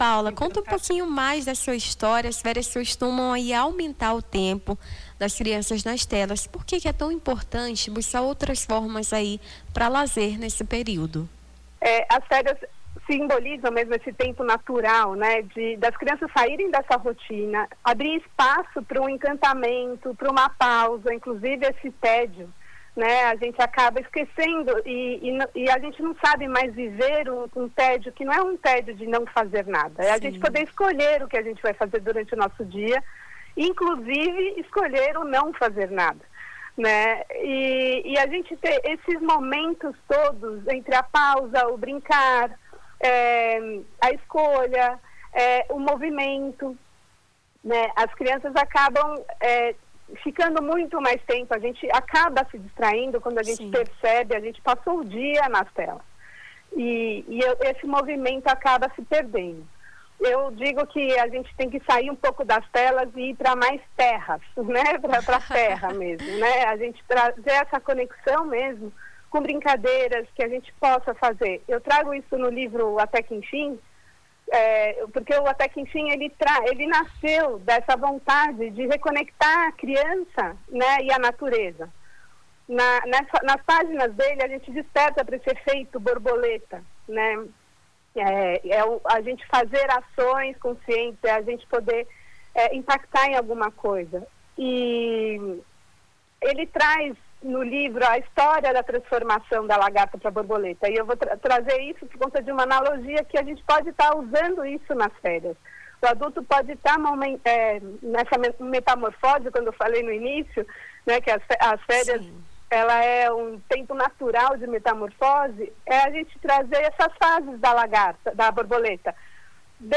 Nome do Artista - CENSURA - ENTREVISTA (DESAFIOS FERIAS ESCOLARES) 15-12-25.mp3